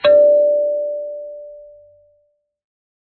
kalimba2_wood-D4-pp.wav